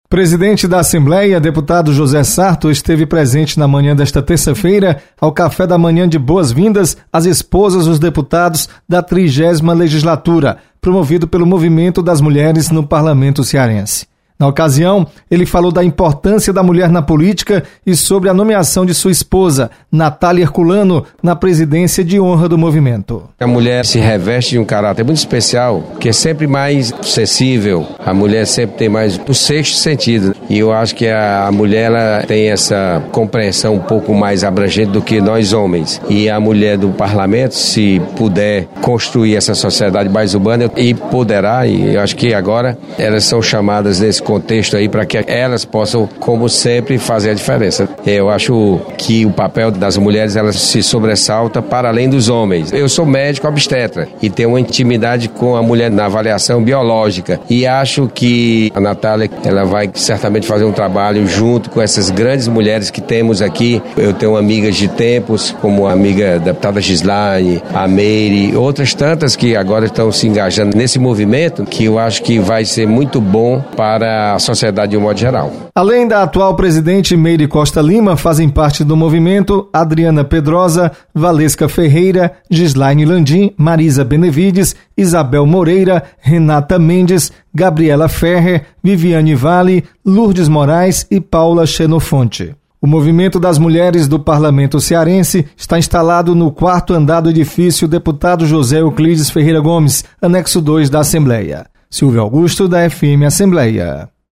Presidente José Sarto destaca atuação do Movimento das Mulheres no Parlamento Cearense. Repórter